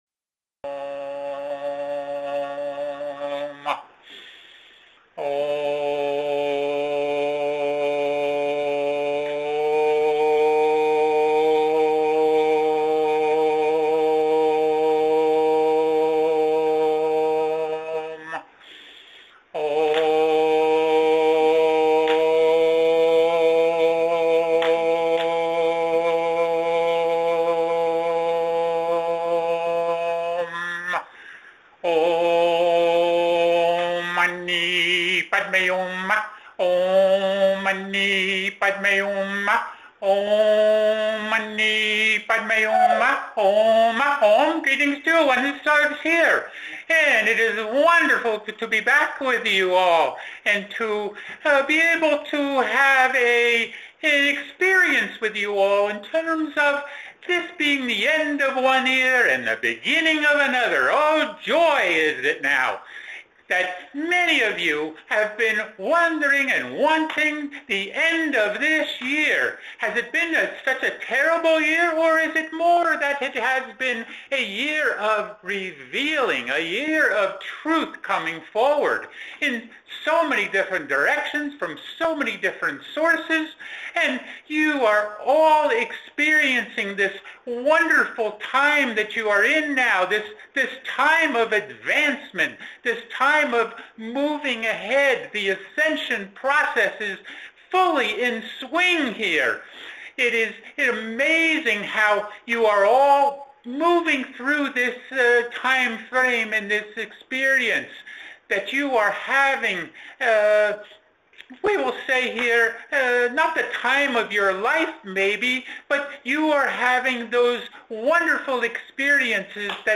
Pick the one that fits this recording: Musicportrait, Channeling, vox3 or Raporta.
Channeling